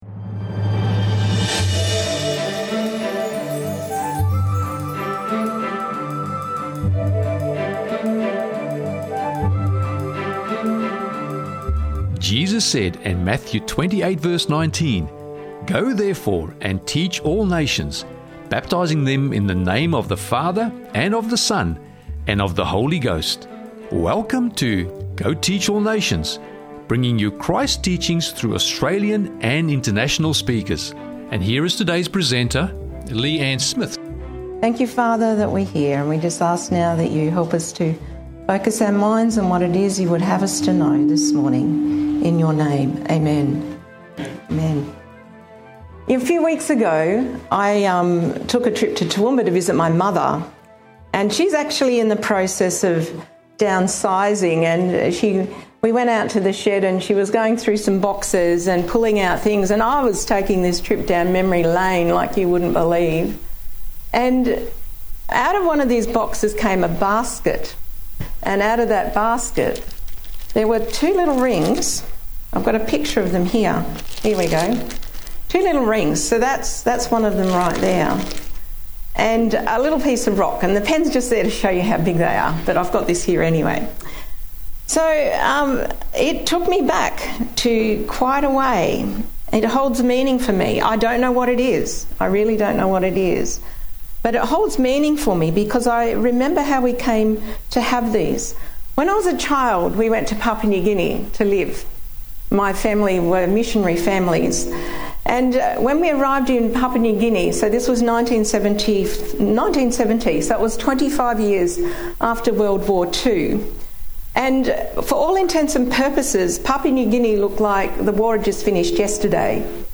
(Sermon Audio)